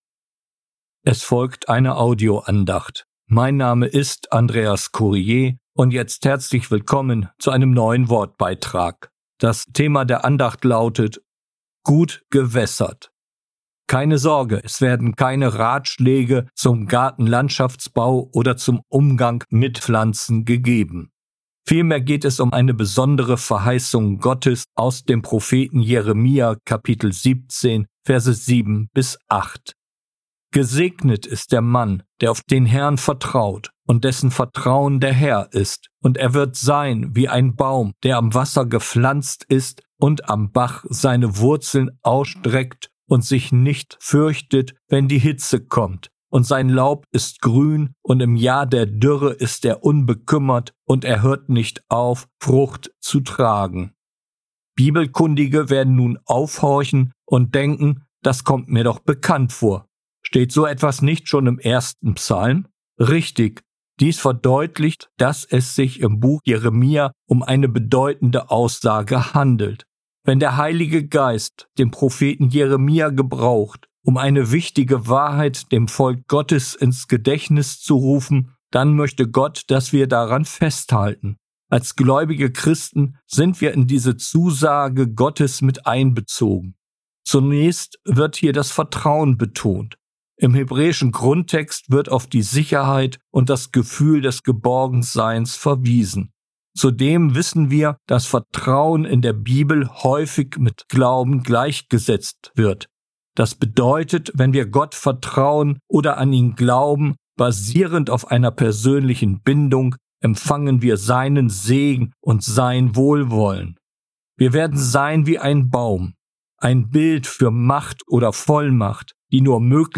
Gut gewässert, eine Audioandacht